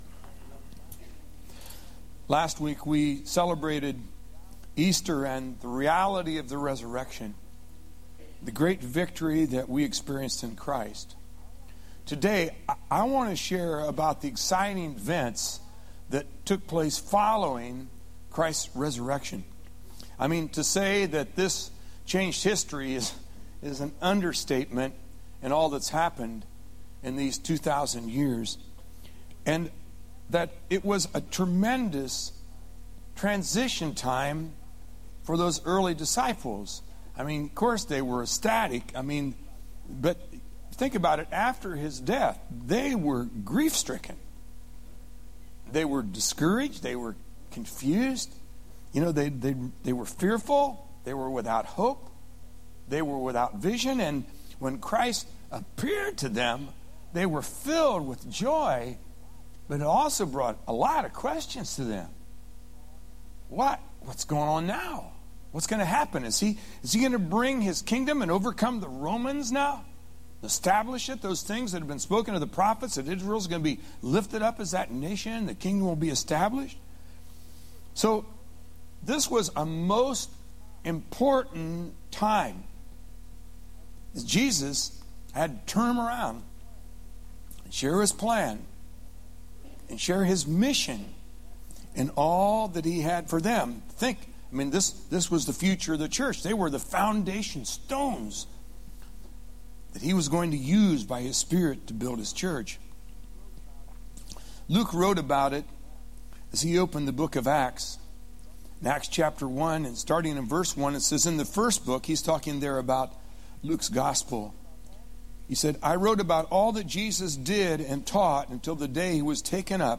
Clearwater Fl. 04/15/07-AM 2nd Service